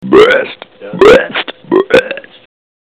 Rülpsen 7 48 KB 1567 Sound abspielen!
ruelpsen7.mp3